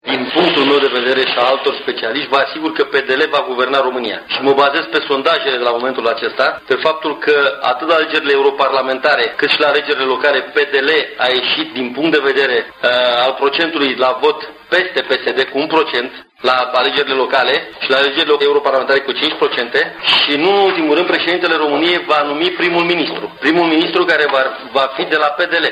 exprimată ieri în conferinţa de presă săptămânală.